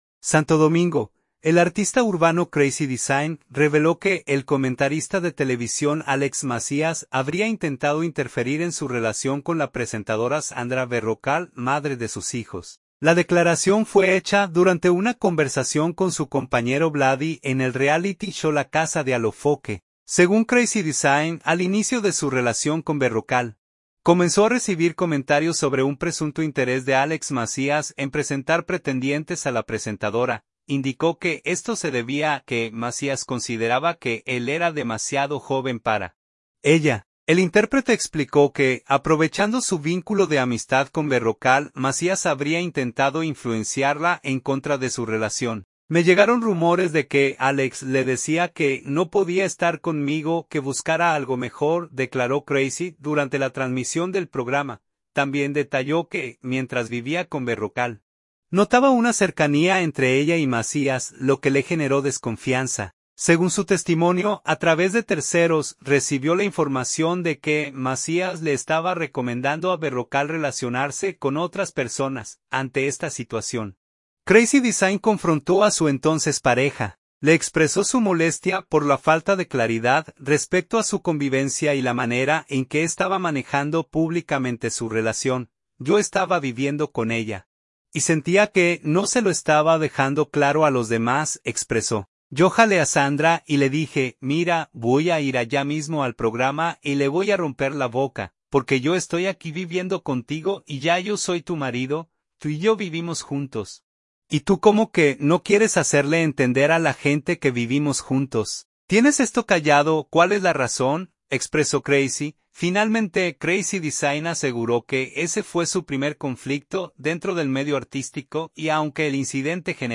La declaración fue hecha durante una conversación